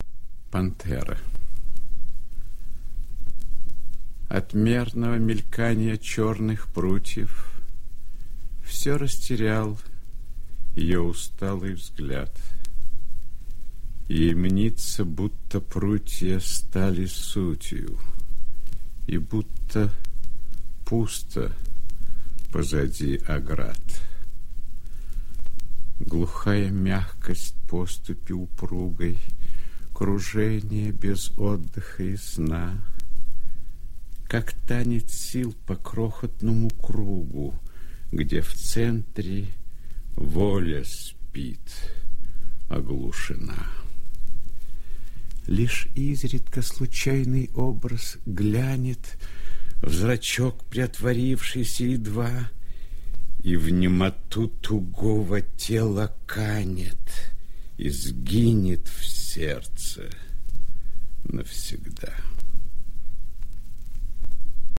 Pantera-chitaet-I.Smoktunovskij-stih-club-ru.mp3